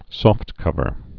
(sôftkŭvər, sŏft-)